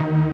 Freq-lead25.ogg